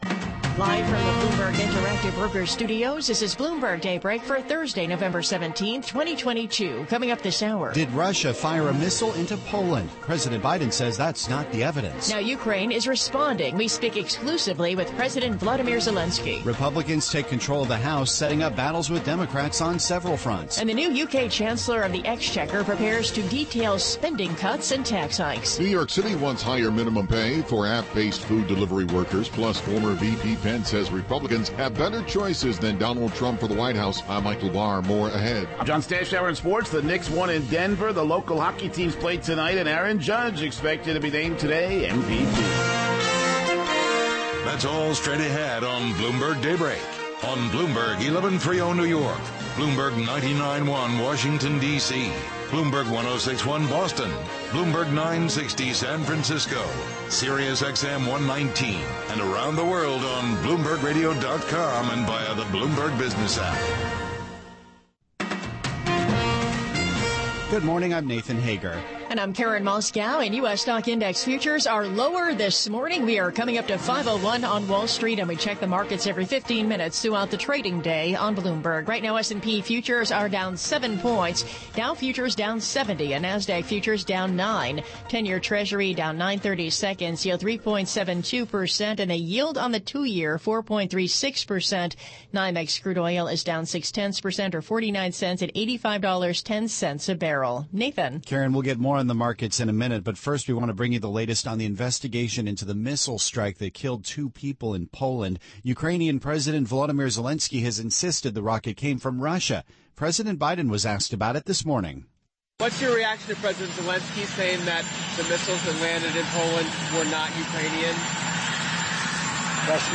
Bloomberg Daybreak: November 17, 2022 - Hour 1 (Radio)
running taped interview of interview with Ukranian President Zelenskiy